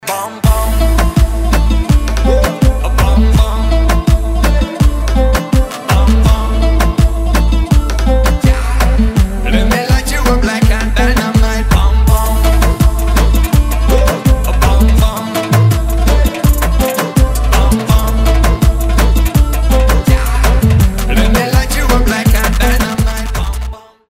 танцевальные
восточные , заводные